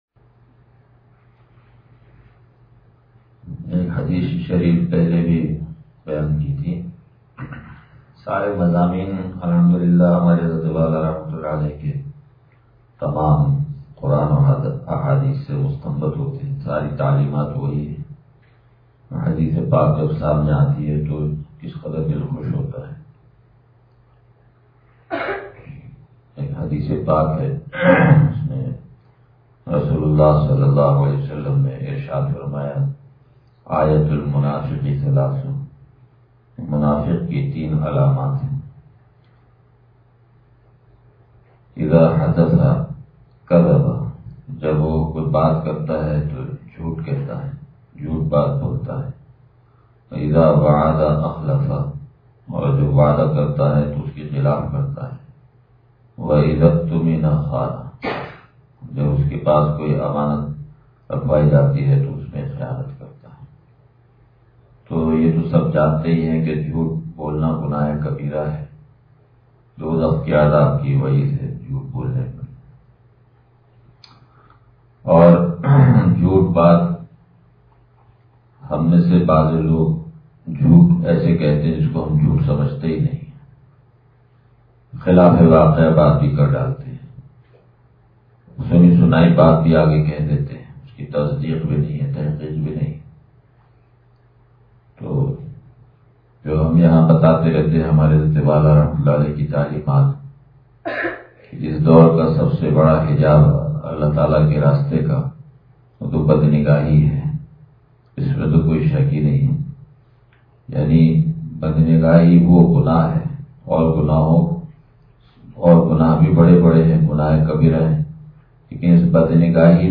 بیان – اتوار